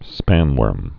(spănwûrm)